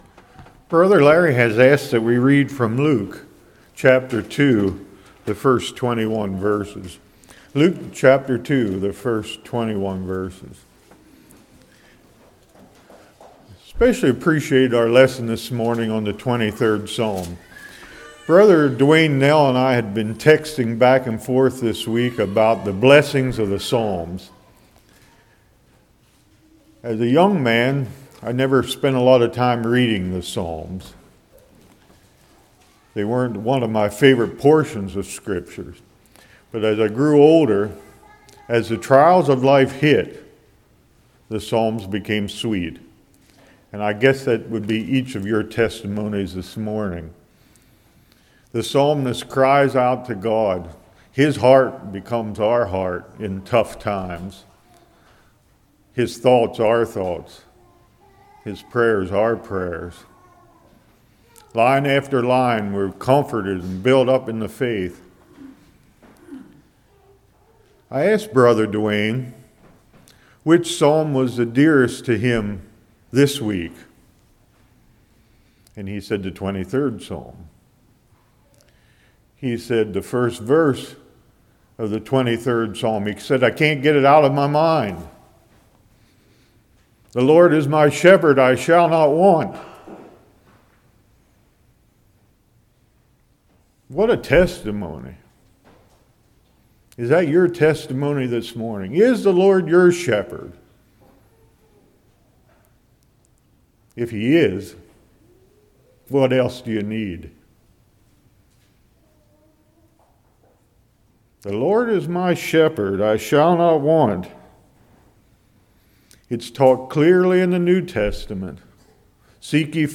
Passage: Luke 2:1-21 Service Type: Morning